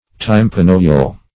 Search Result for " tympanohyal" : The Collaborative International Dictionary of English v.0.48: Tympanohyal \Tym`pa*no*hy"al\, a. (Anat.)
tympanohyal.mp3